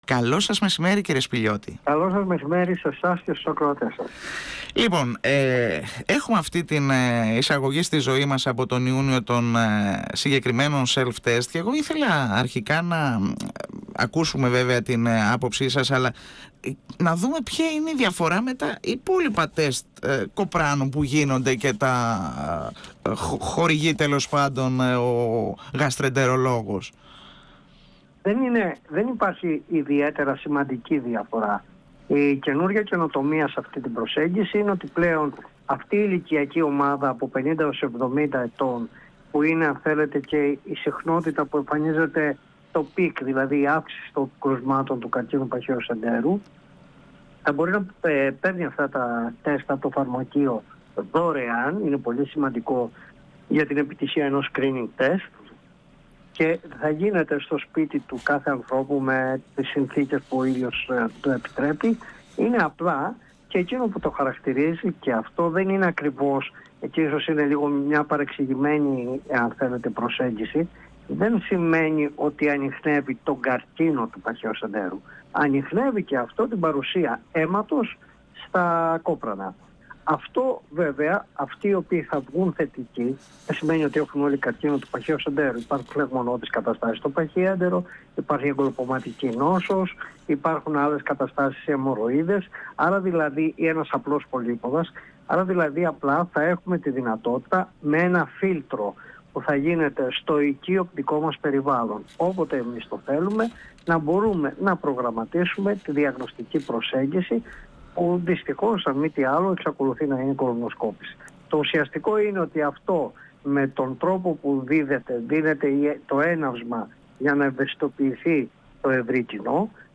μιλώντας στην εκπομπή «Εδώ και Τώρα» του 102FM της ΕΡΤ3.